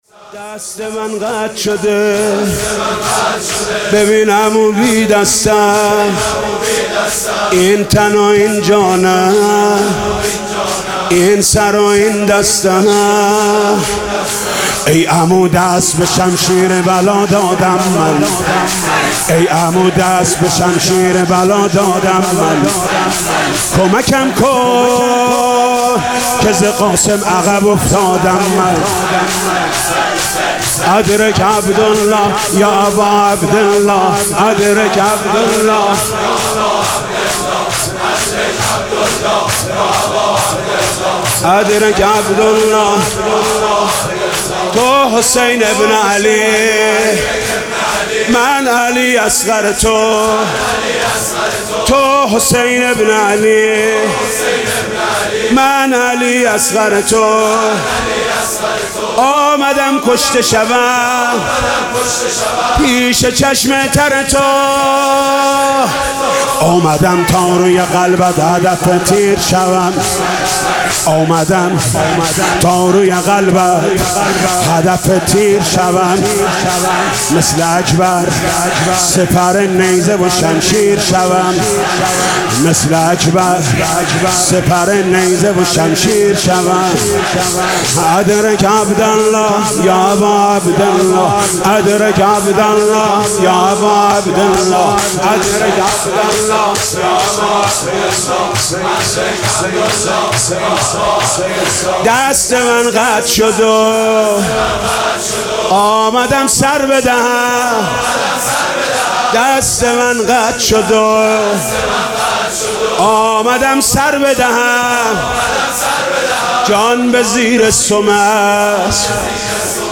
مداحی‌های روز پنجم محرم، نوای نینوا